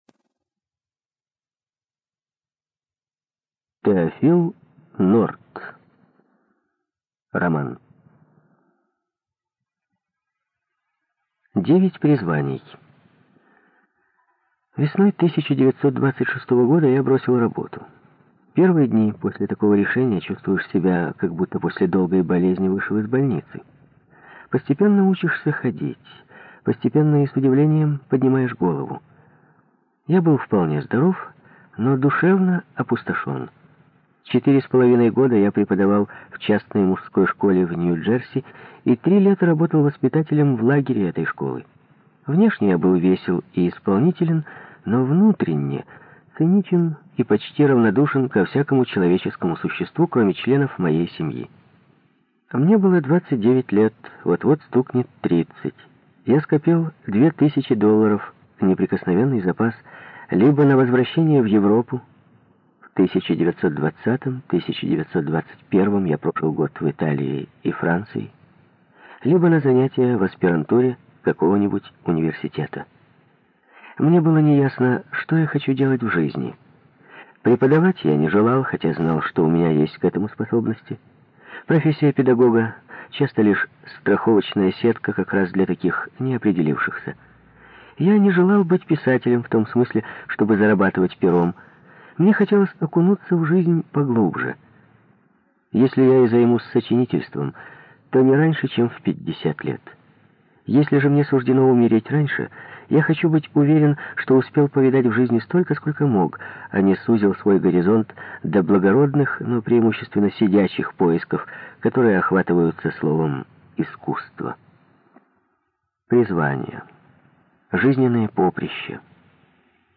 ЖанрКлассическая проза
Студия звукозаписиРеспубликанский дом звукозаписи и печати УТОС